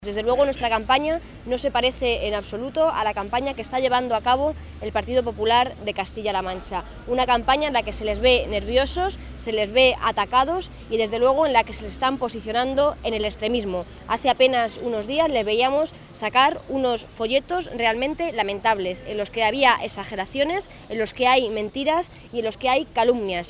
Isabel Rodríguez, atendiendo a los medios